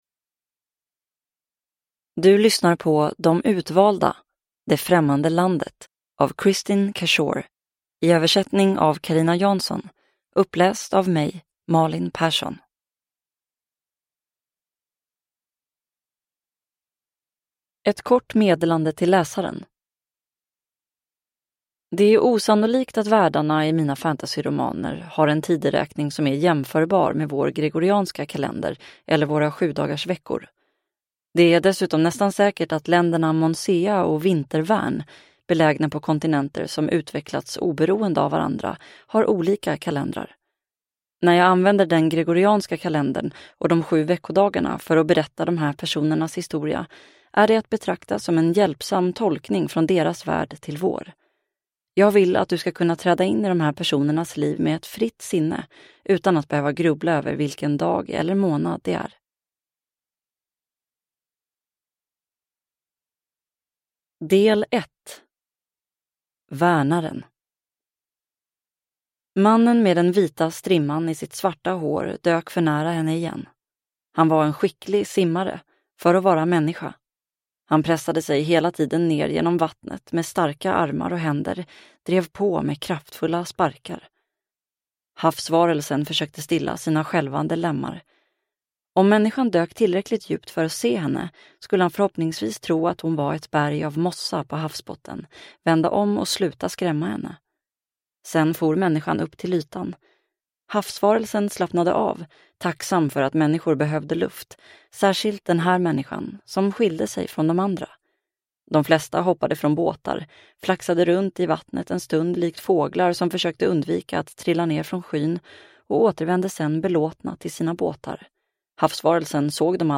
Det främmande landet – Ljudbok – Laddas ner